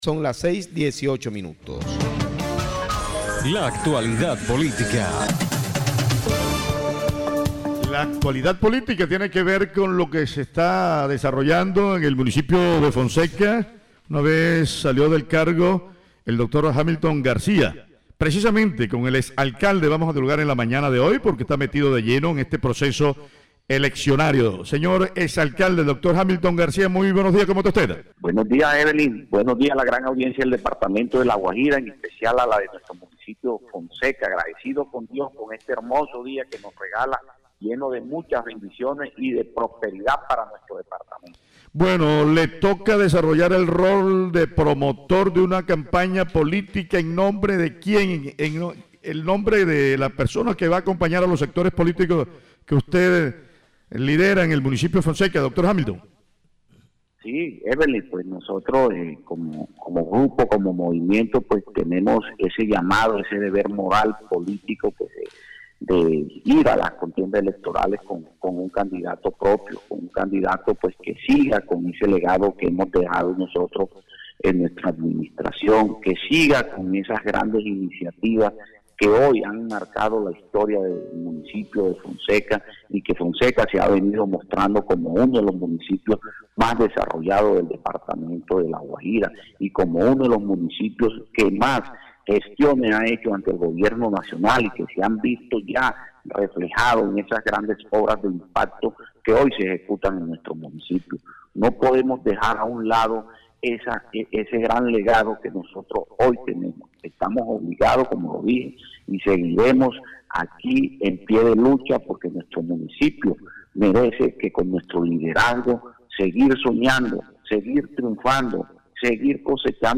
Voz-Hamilton-Garcia-–-Ex-alcalde-de-Fonseca.mp3